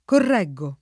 correggere [ korr $JJ ere ]